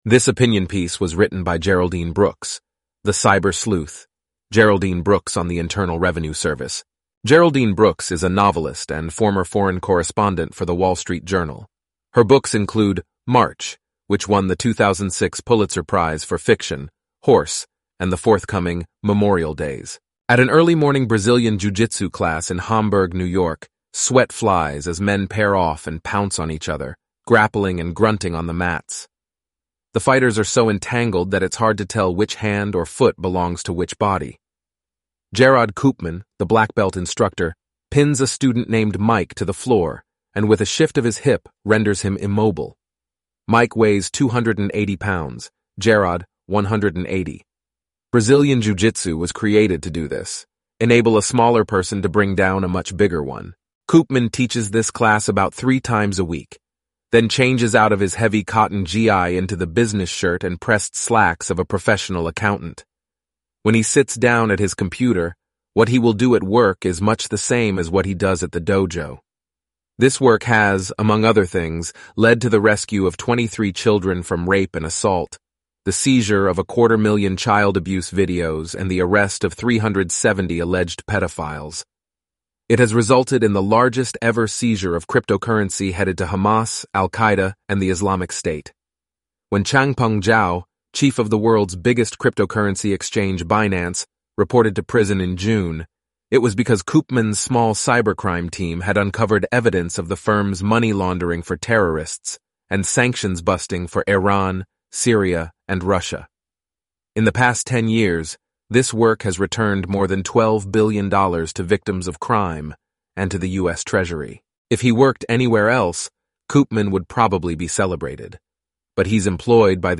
eleven-labs_en-US_Antoni_standard_audio.mp3